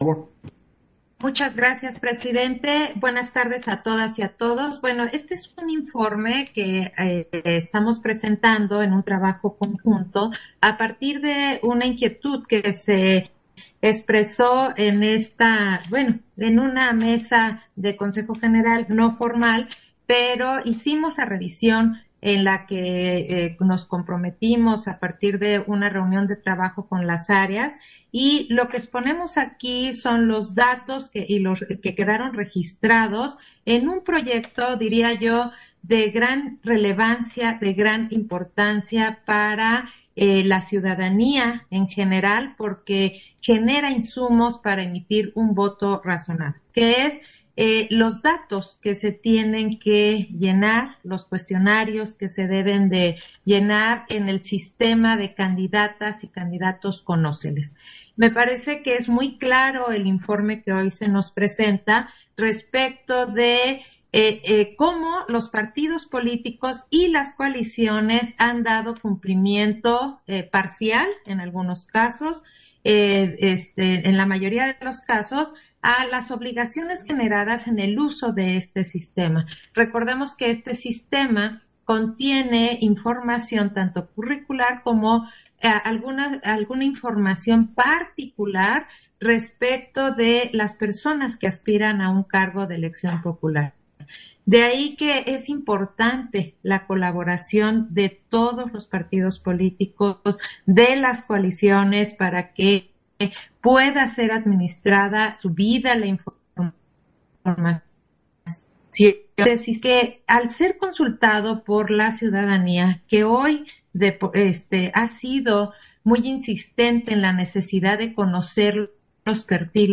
Intervención de Claudia Zavala, en el punto de la Sesión Extraordinaria, relativo al informe en relación con el sistema denominado, Candidatas y Cadidatos, Conóceles